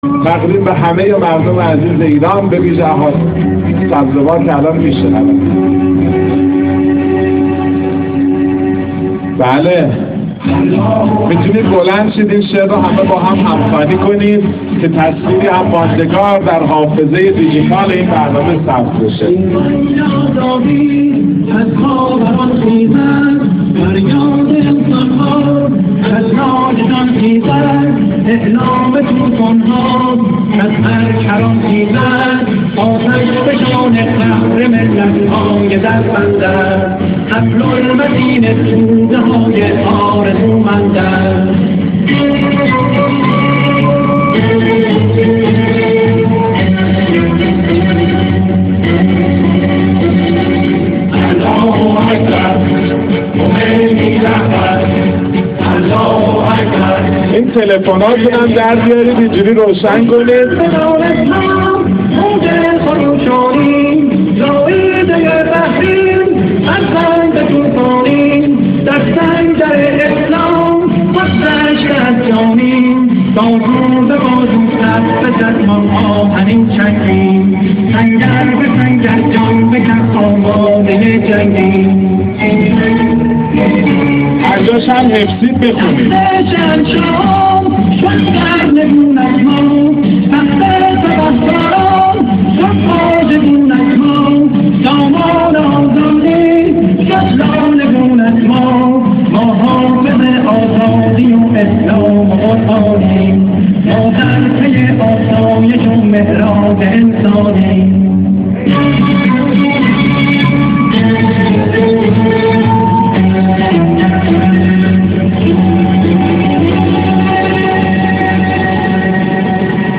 اختتامیه جشنواره ملی سرود «چله انقلاب»+هم خوانی بانگ آزادی
در این مراسم محمد گلریز، که تاکنون شعرهای زیادی از شاعران انقلابی از جمله حمید سبزواری را اجرا کرده است با ذکر خاطره‌ای از دیدار با امام خمینی(ره) و دعای آن رهبر حکیم و فرزانه برای وی و استاد سبزواری به اجرای زنده شعر«بانگ آزادی» در رابطه با انقلاب اسلامی از اشعار زنده یاد حمید سبزواری پرداخت.
فایل صوتی اجرای بانگ آزادی توسط محمد گلریز در این مراسم